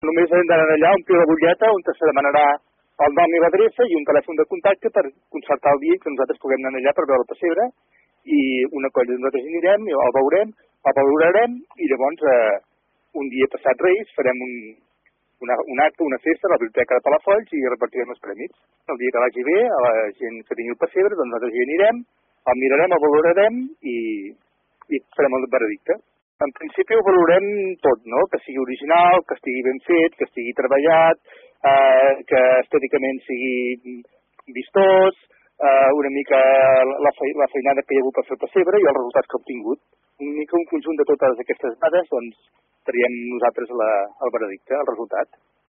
Són declaracions al Palafolls en Xarxa